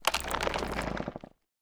Minecraft Version Minecraft Version 1.21.5 Latest Release | Latest Snapshot 1.21.5 / assets / minecraft / sounds / mob / creaking / creaking_idle4.ogg Compare With Compare With Latest Release | Latest Snapshot
creaking_idle4.ogg